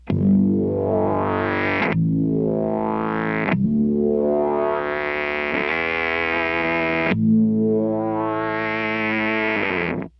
The EHX Bass Micro Synthesizer is a pure bass guitar synthesizer with all analog circuitry. Adjustable synth parameters deliver fat, sweet tones from another world.
An Attack Decay control creates bowed effects or ominous volume swells while the Filter Sweep section sweeps through the full frequency spectrum for sound from quick high to low chops and slow low to high blooms.
Lo to Hi Filter Sweep
Bass-Micro-SynthAnalog-Microsynth-Lo-to-Hi-Filter-Sweep.mp3